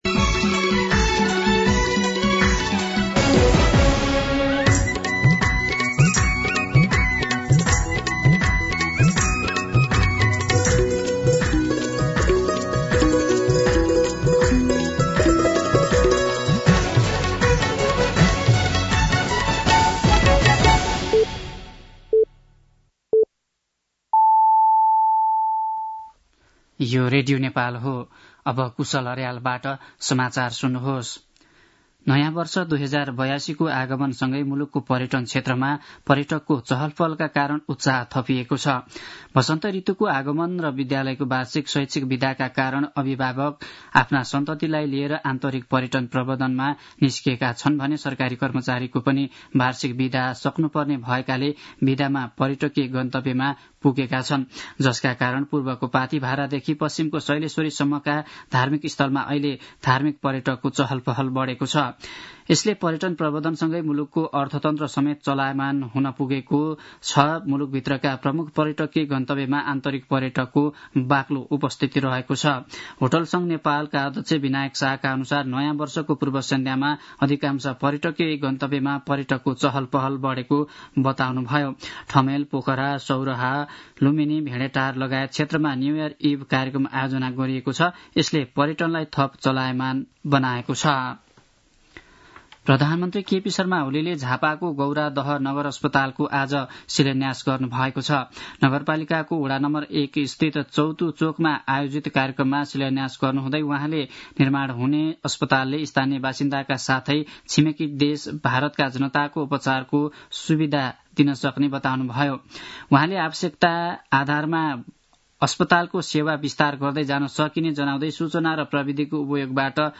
दिउँसो ४ बजेको नेपाली समाचार : ३१ चैत , २०८१
4-pm-Nepali-News-3.mp3